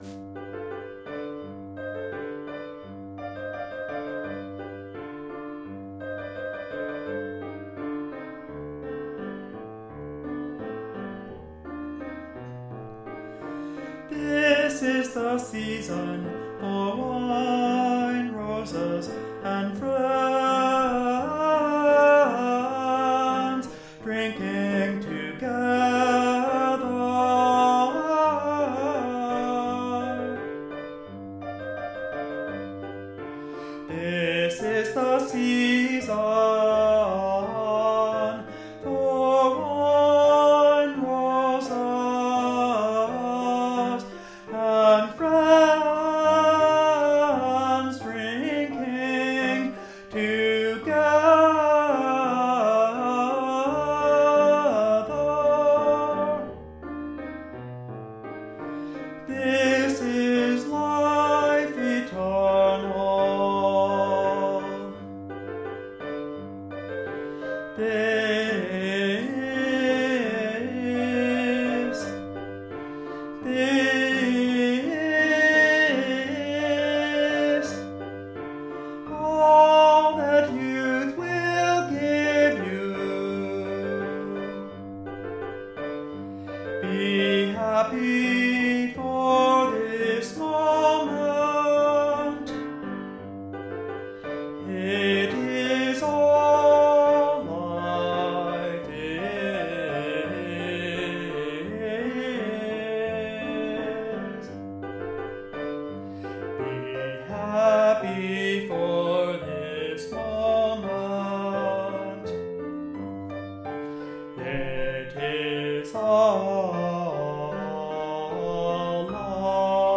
tenor
for soprano or tenor and piano